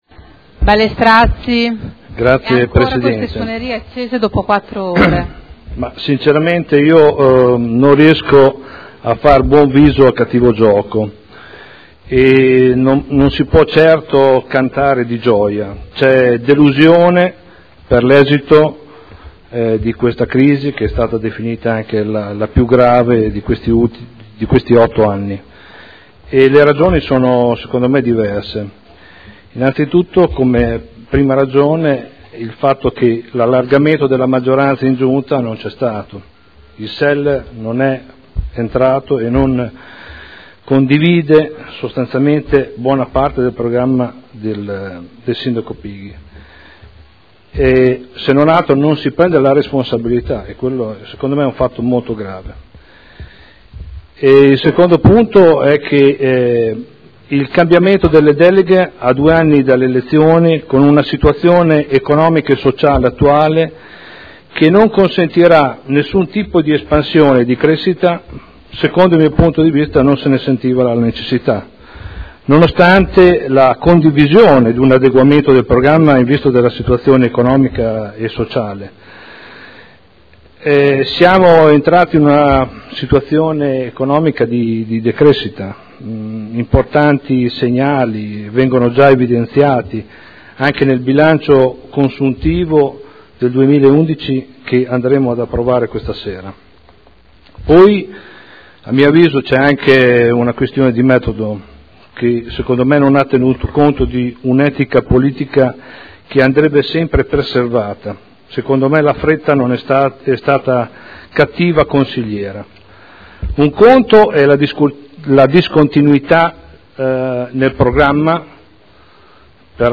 Stefano Prampolini — Sito Audio Consiglio Comunale